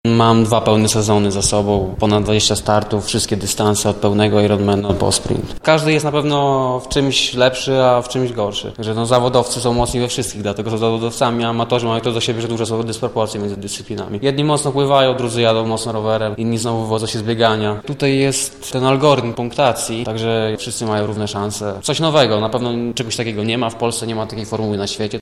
mówi uczestnik Insane Triathlonu